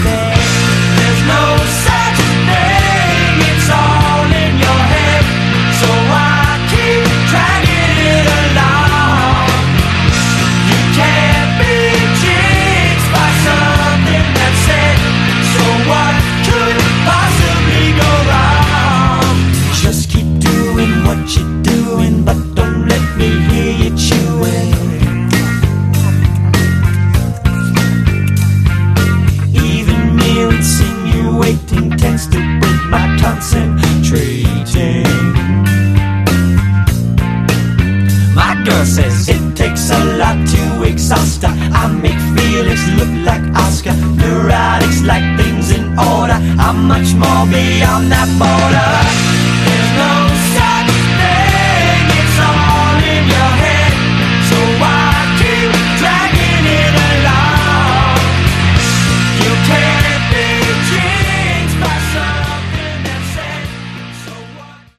Category: Glammish Hard Rock
lead vocals
drums
bass, vocals
guitar, vocals
backing vocals